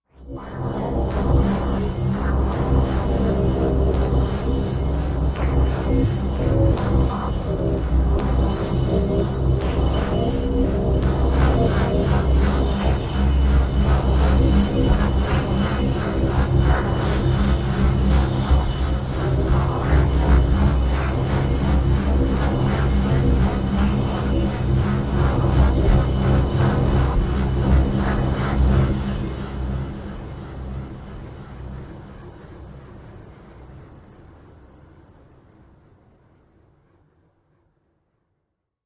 Just Below Low Electric Wet Sweeps Medium Pulses